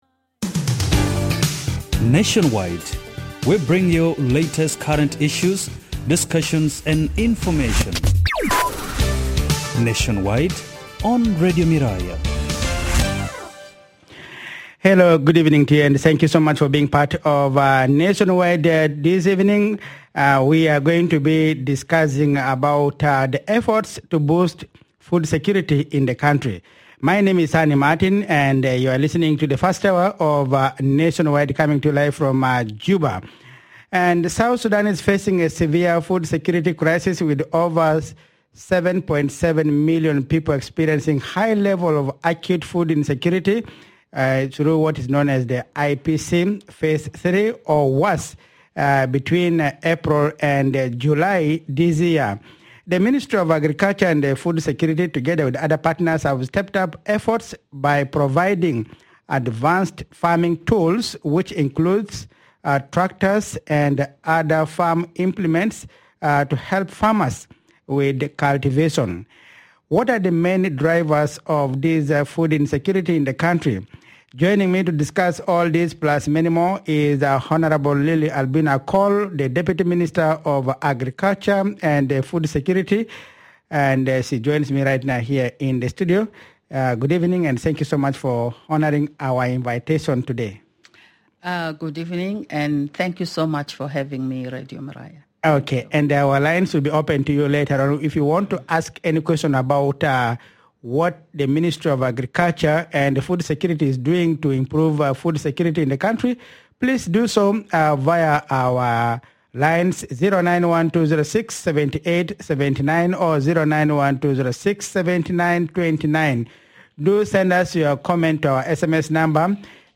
Guest: - Hon Lily Alino Akol, Deputy Minister of Agriculture and Food Security